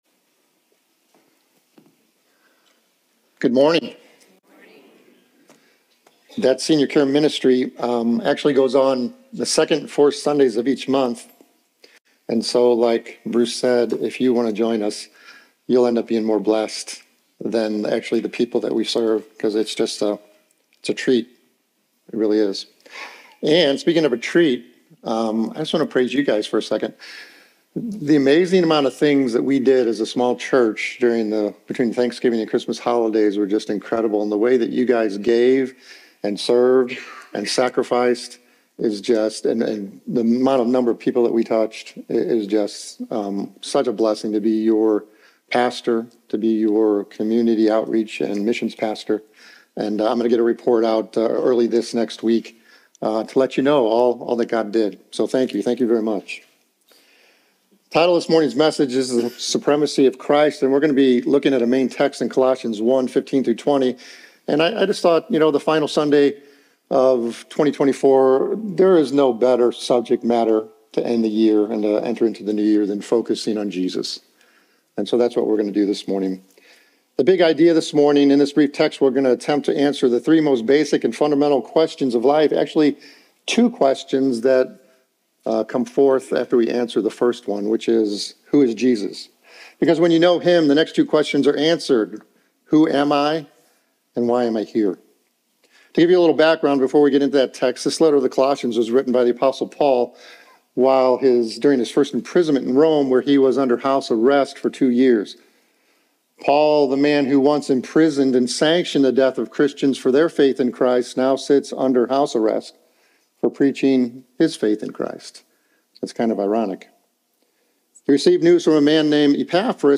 Stand Alone Sermon 12/29/24